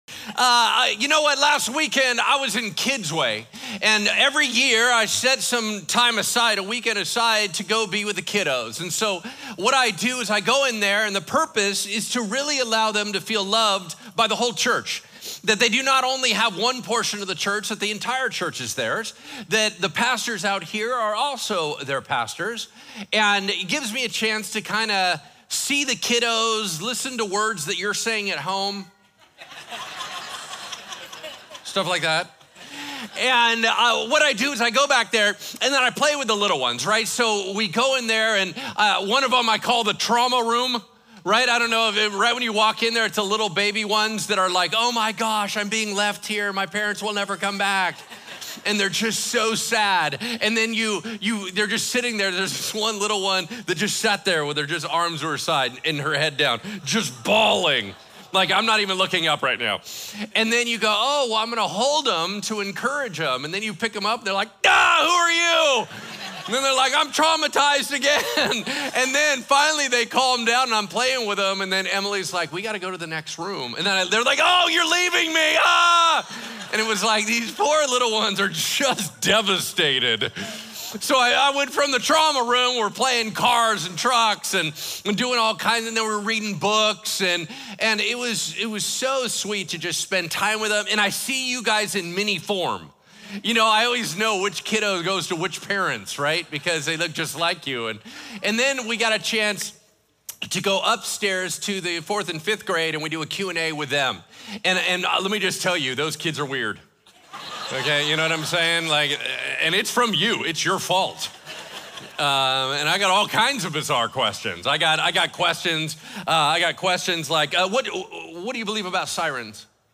Weekly Sermon